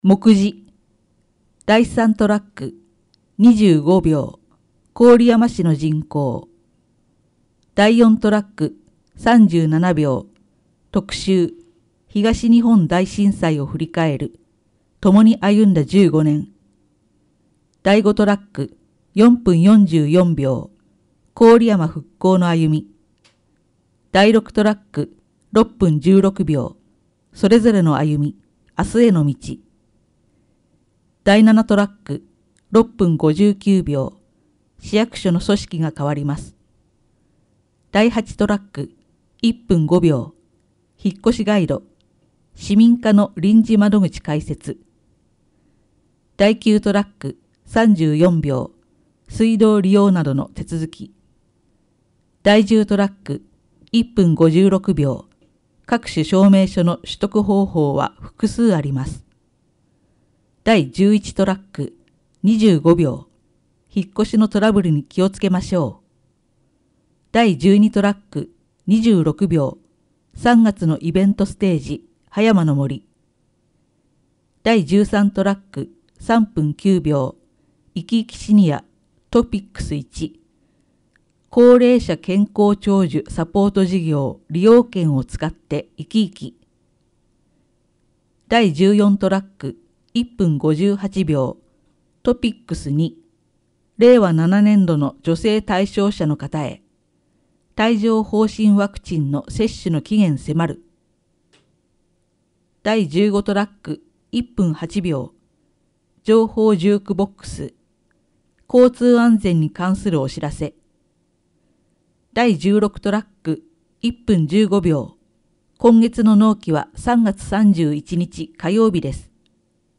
「声の広報」は、「広報こおりやま」の一部記事を「視覚障がい者支援ボランティアグループ　くるみ会」の皆さんが読み上げています。